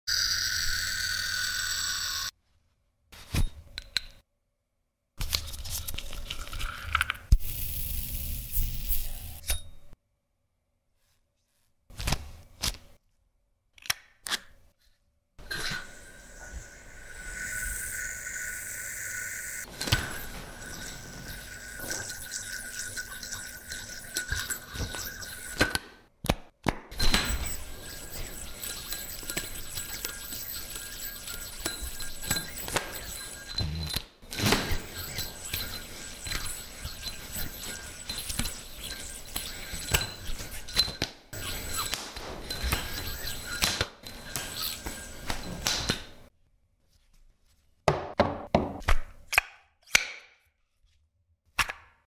🔊SOUND ON📢The ultimate undersized yo yo sound effects free download
About 🔊SOUND ON📢The ultimate undersized yo-yo Mp3 Sound Effect